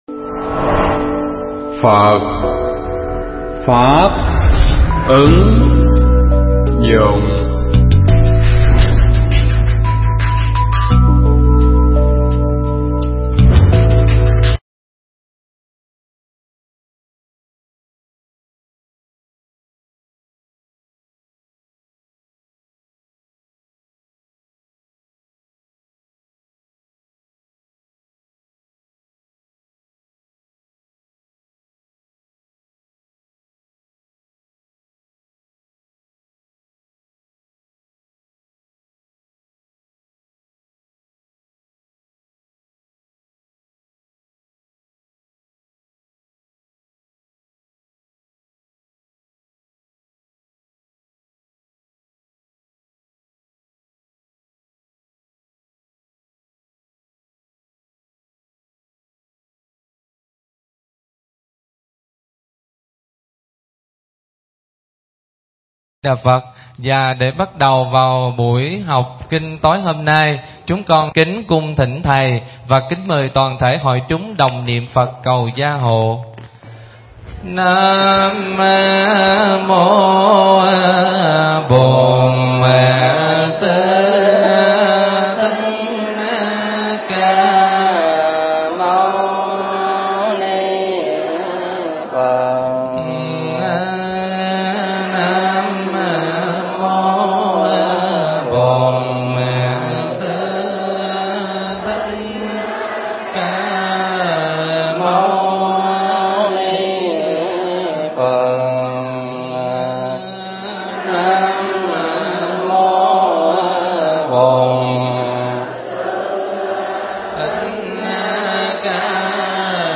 pháp thoại Kinh Pháp Cú Phẩm Thế Gian (Câu 167)